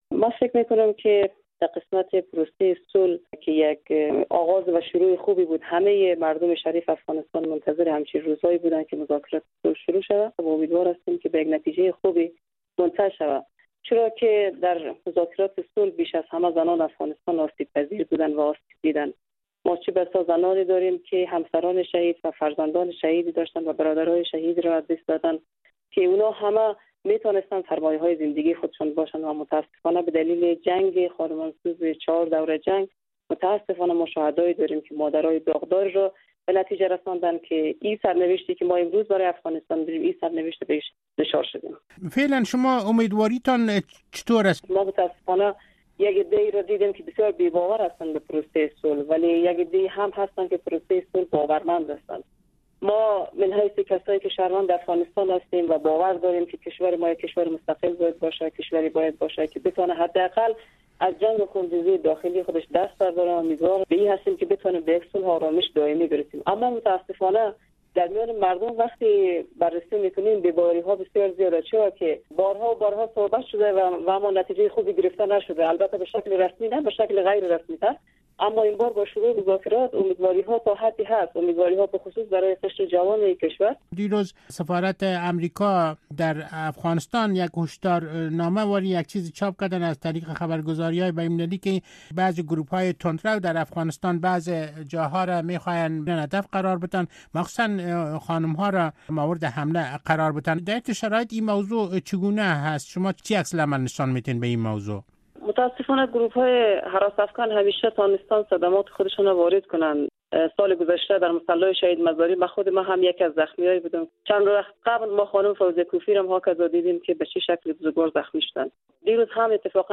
معصومه مرادی والی سابق ولایت دایکندی در برنامه زیر آسمان کبود رادیو آزادی در مورد مذاکرات بین افغان‌ها در قطر گفت که تأمین صلح آرزوی دیرینه مردم آفغانستان است و ابراز امیدواری کرد که از مذاکرات نتیجه مثبت به دست آید.